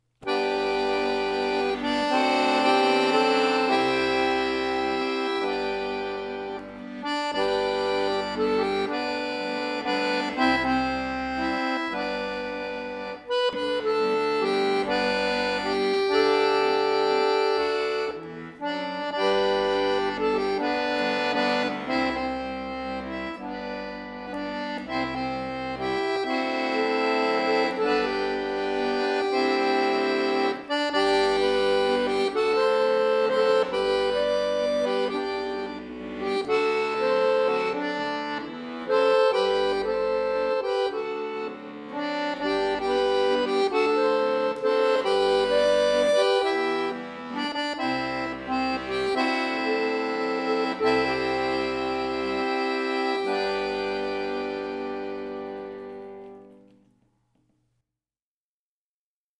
Accordion Solo
Folk
Scottish